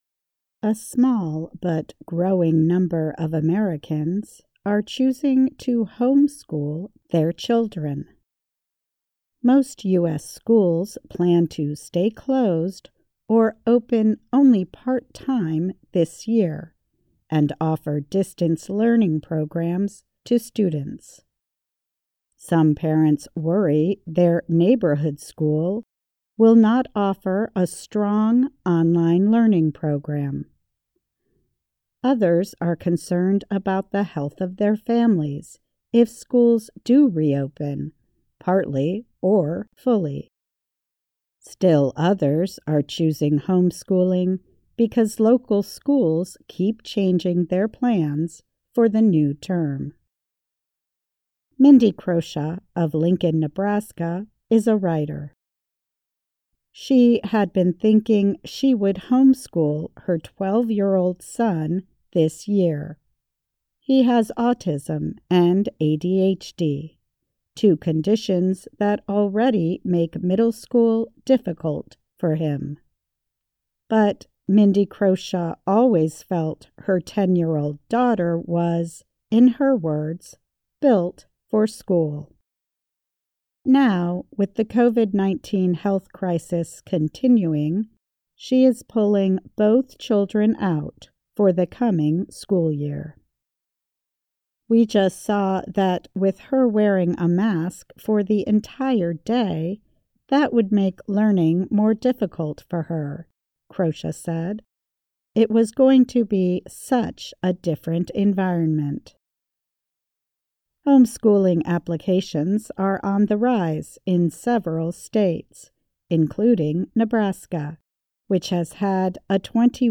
慢速英语:美国父母在疫情期间增加了线上学习的兴趣